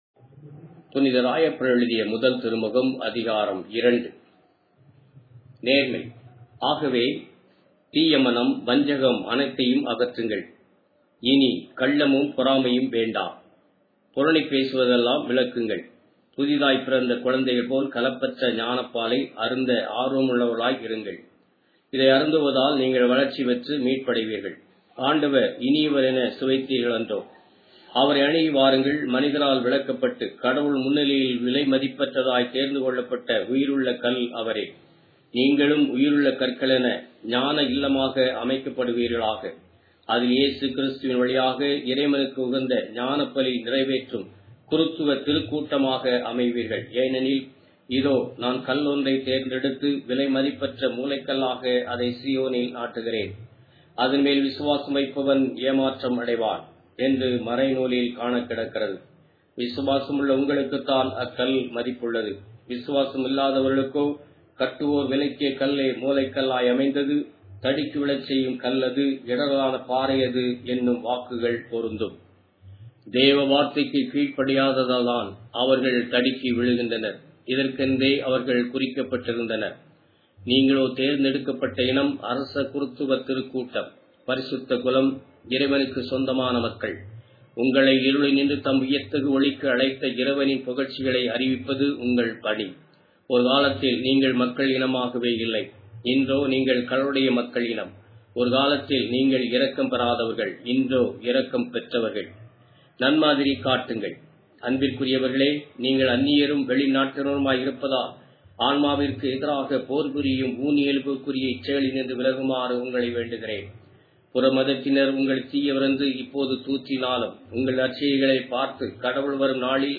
Tamil Audio Bible - 1-Peter 1 in Rcta bible version